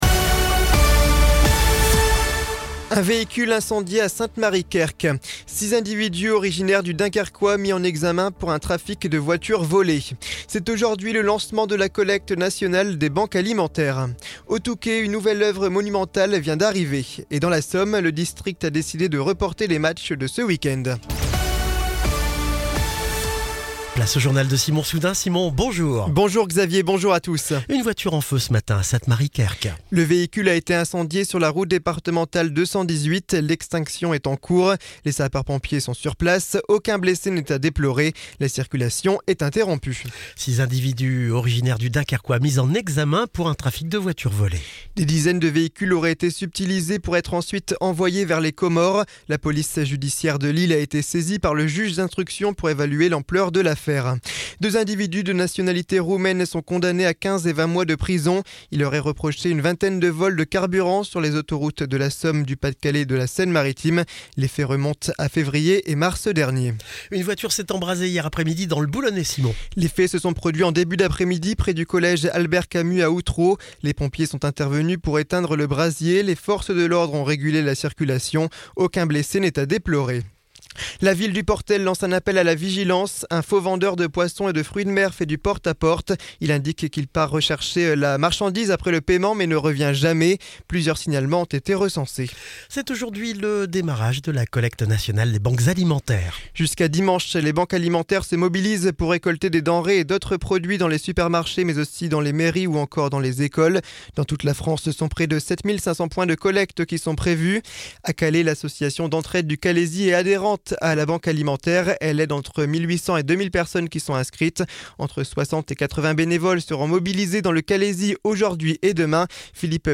Le journal de ce vendredi 22 novembre 2024